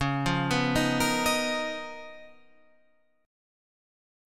C#13 chord